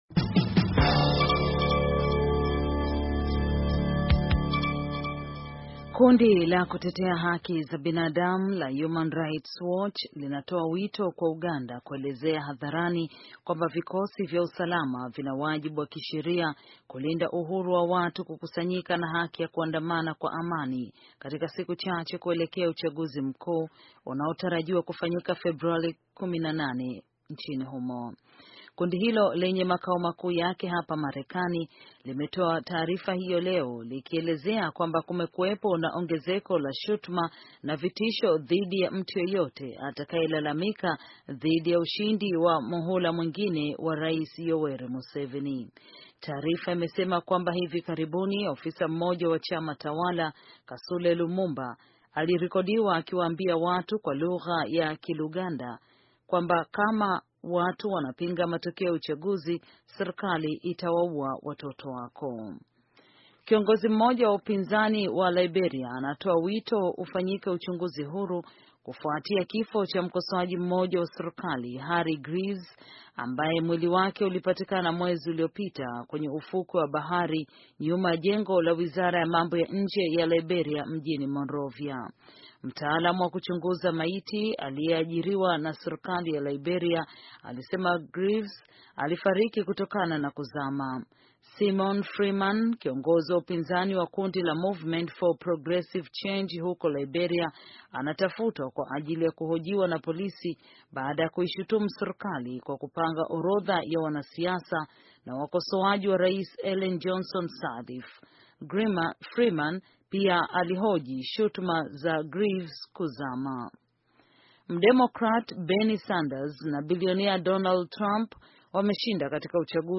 Taarifa ya habari - 5:34